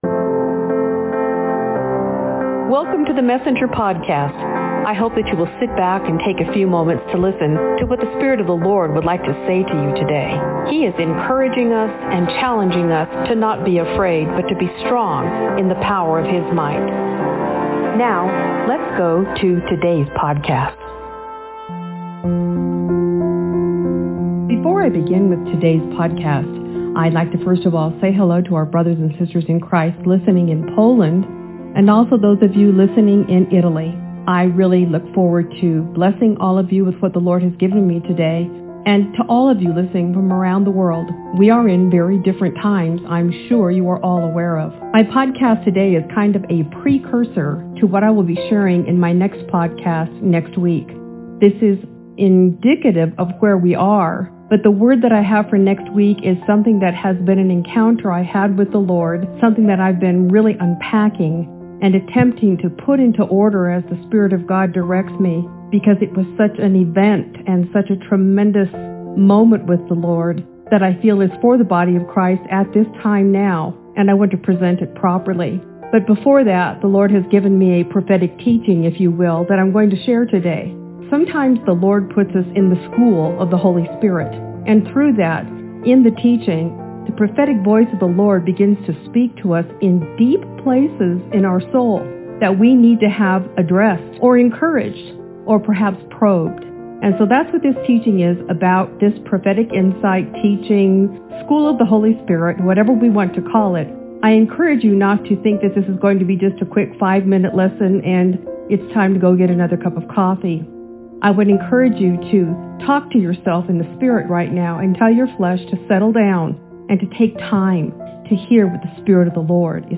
( Prophetic exhortation and words of knowledge not included in written message continue in the audio message format beginning at audio player time marker 21:38. )